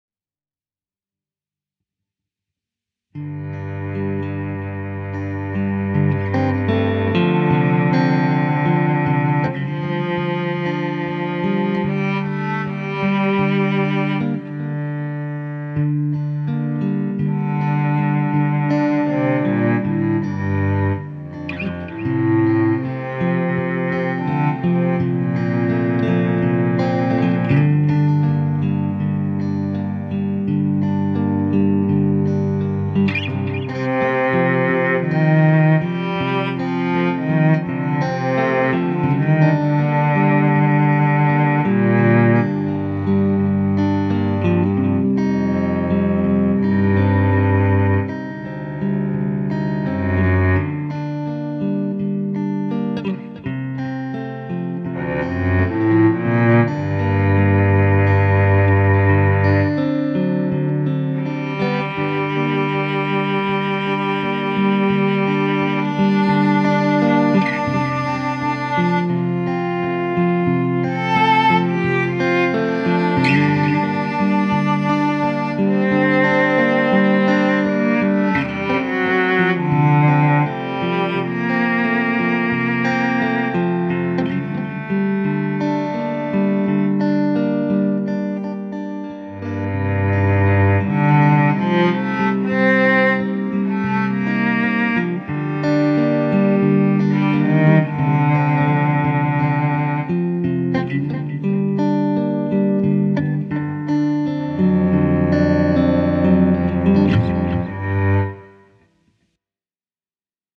Ja just nyt tutkiskelen sellon soittoa sampleillä, ensimmäinen kokeilu miten oikea kitara ja samplesello toimisivat yhdessä
Mutta tämähän on sitä harrastajan yksinräpellystä eikä studiotoimintaa.